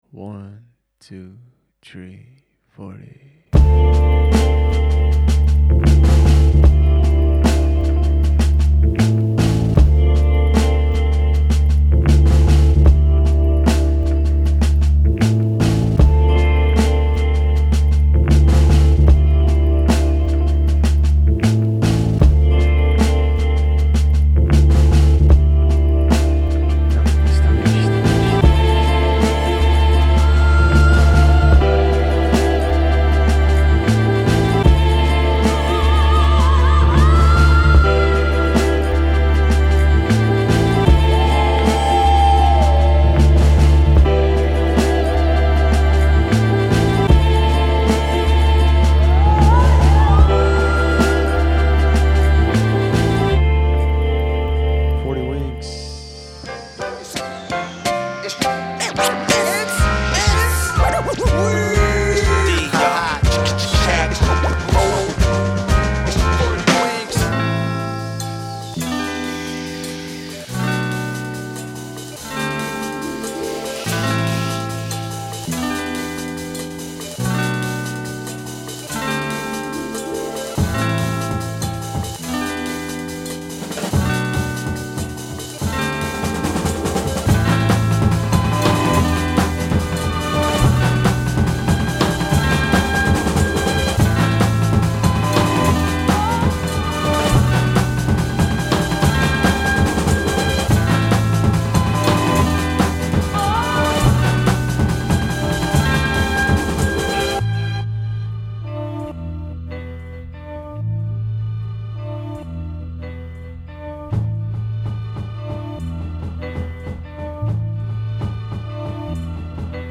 Underground Hip Hop
Belgian production duo